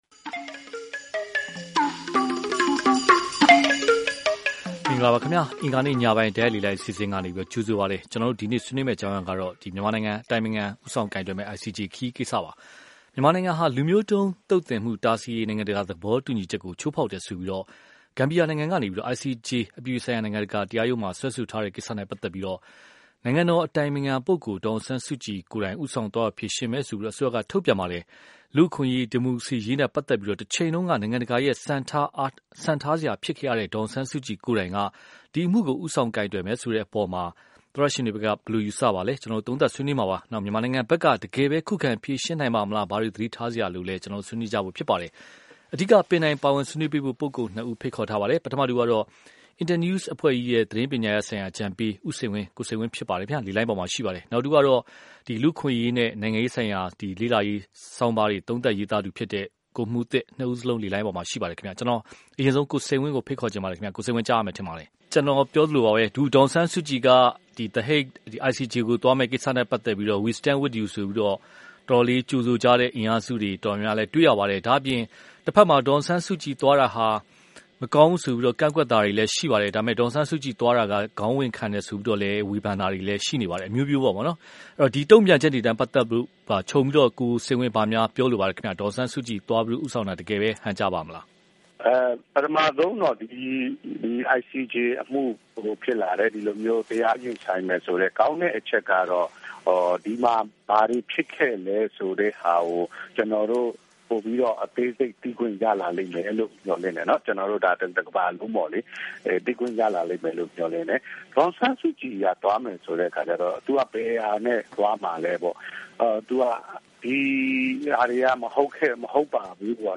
အတိုင်ပင်ခံဦးဆောင်ကိုင်တွယ်မယ့် ICJ ခရီး (တိုက်ရိုက်လေလှိုင်း)